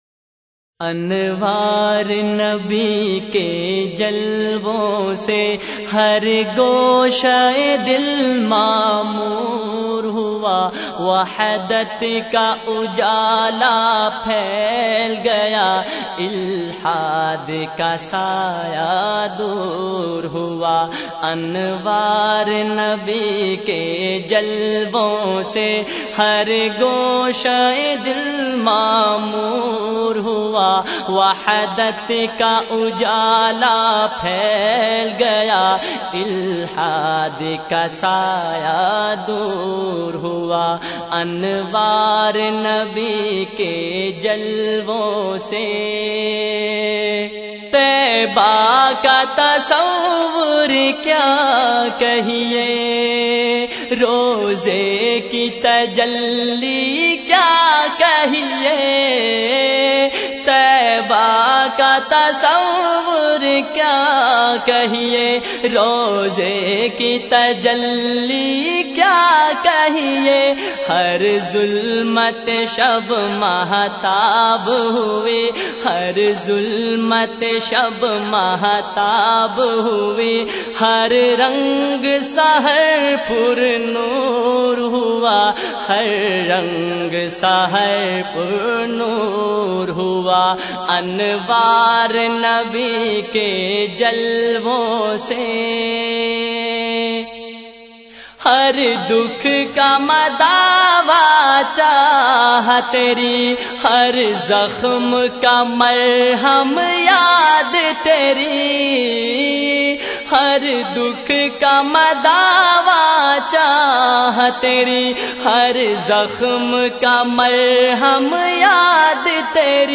naat
Heart-Touching Voice